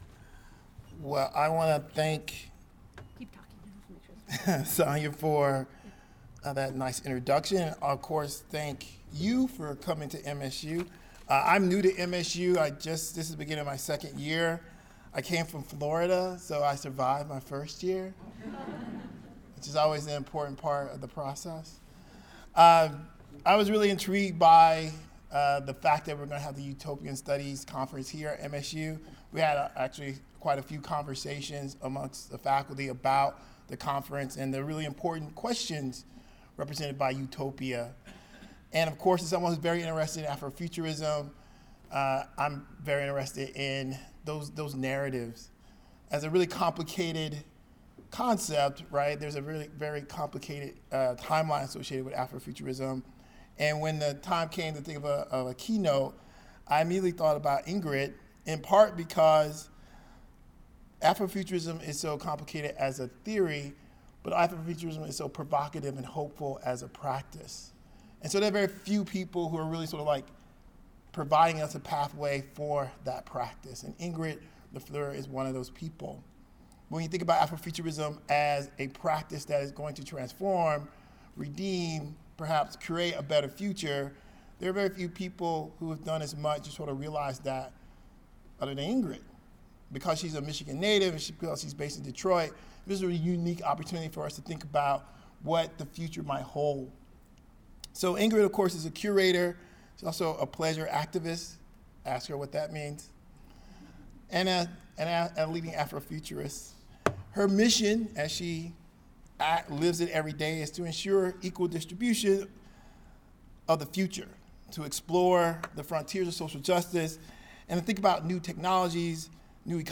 Utopian Studies Society meeting at Michigan State University
Speeches (compositions)